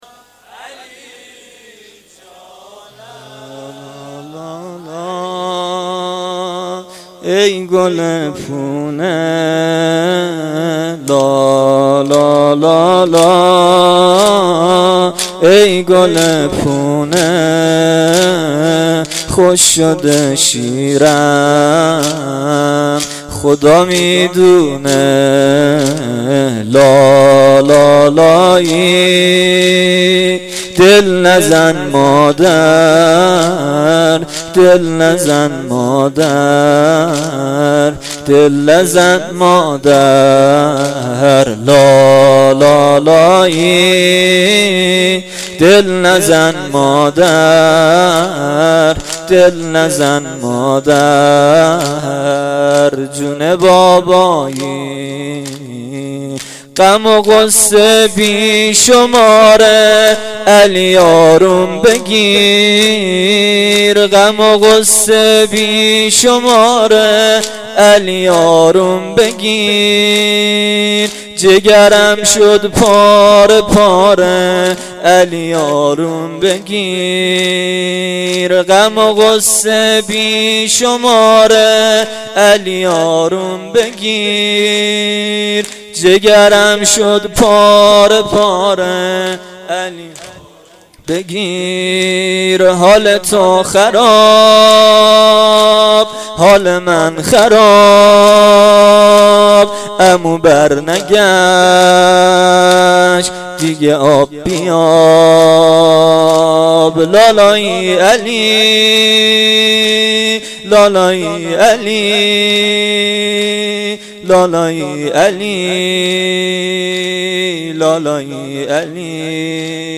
زمینه شب هفتم محرم1393